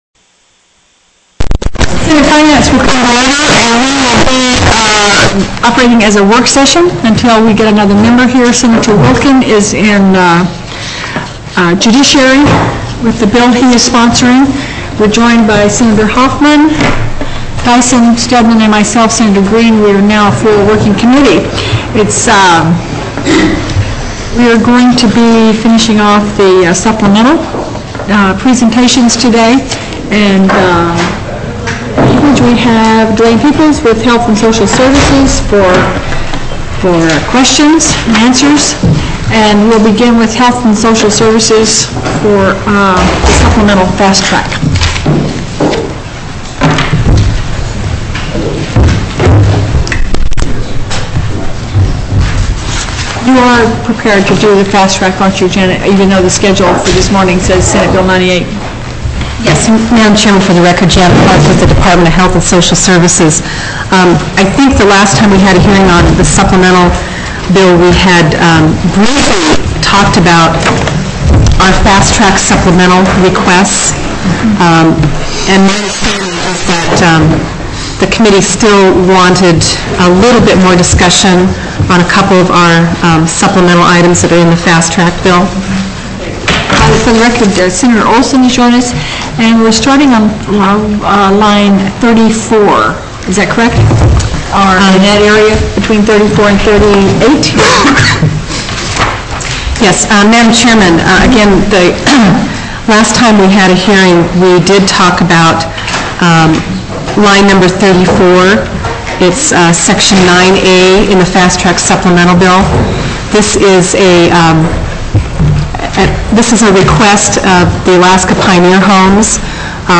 Attending via Teleconference: From Anchorage: